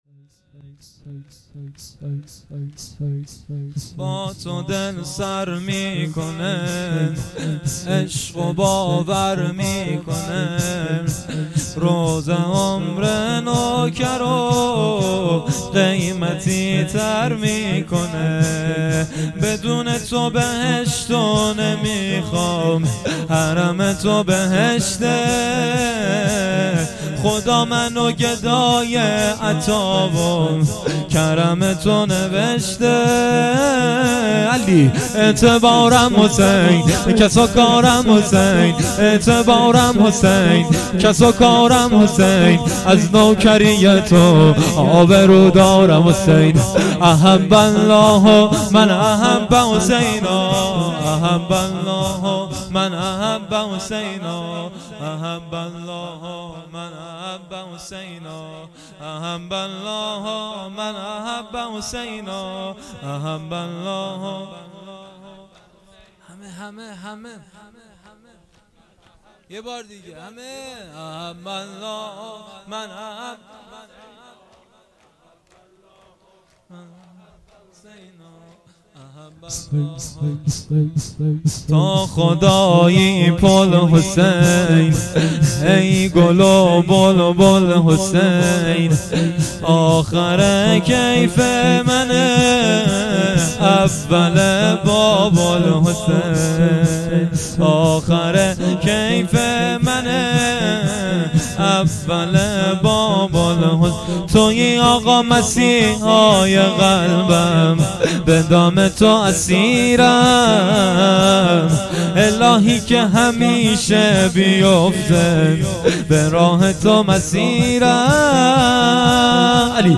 شور
مراسم عزاداری دهه اول محرم الحرام 1399 - گلزار شهدای هرمزآباد